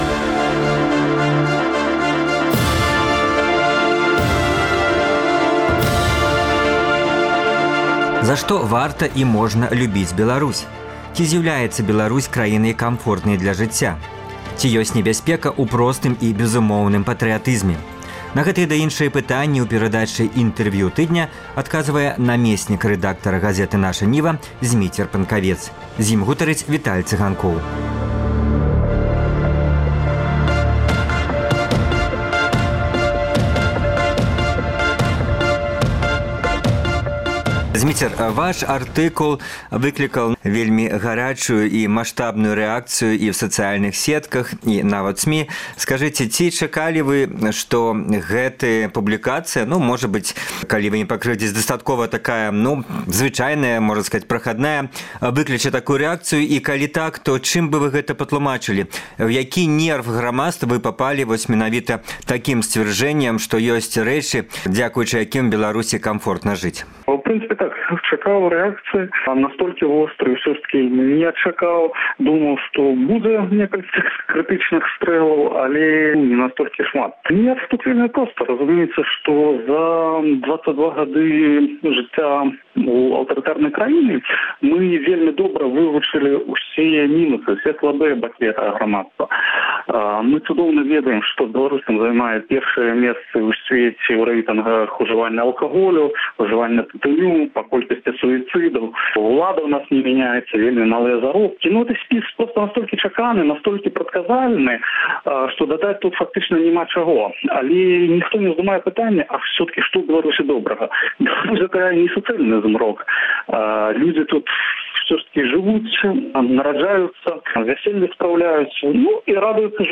Інтэрвію тыдня